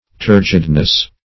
Turgid \Tur"gid\ (t[^u]r"j[i^]d), a. [L. turgidus, from turgere